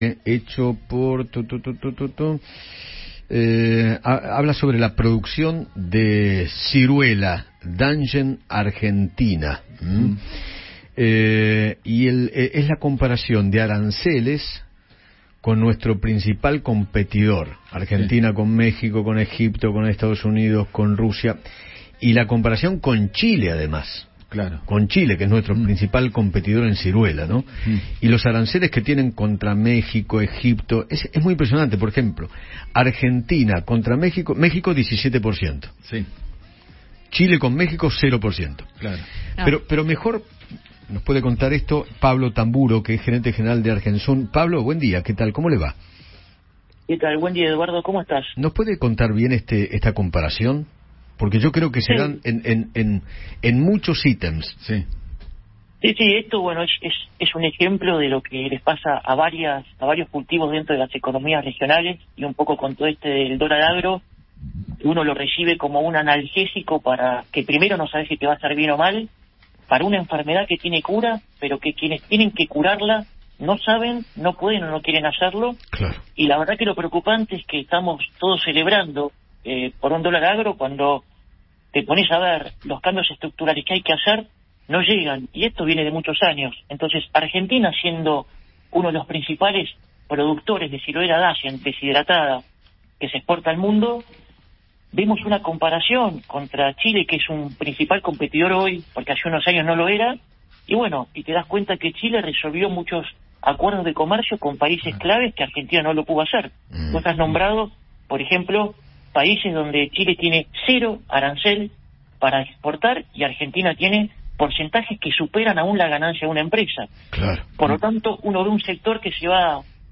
dialogó con Eduardo Feinmann sobre la producción de ciruelas en Argentina y la diferencia que hay con los países competidores.